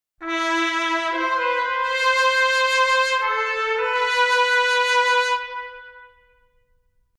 Микрофонные позиции медных